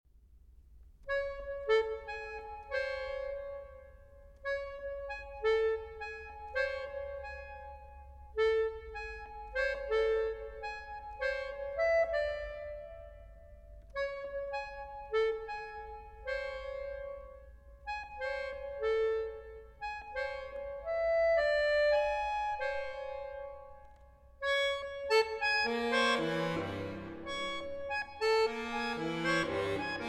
for accordion solo
Accordion Solo   11:03 € 1,45